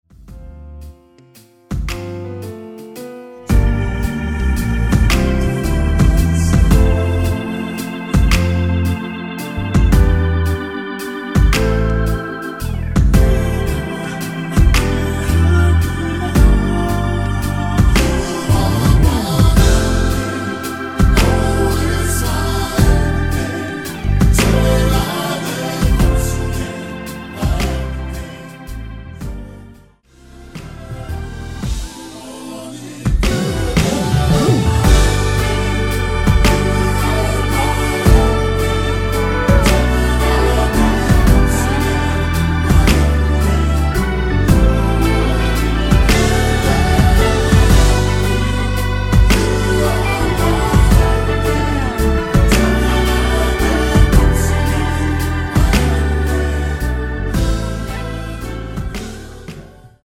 (-3) 내린코러스 포함된 MR 입니다.(미리듣기 참조)
앞부분30초, 뒷부분30초씩 편집해서 올려 드리고 있습니다.
위처럼 미리듣기를 만들어서 그렇습니다.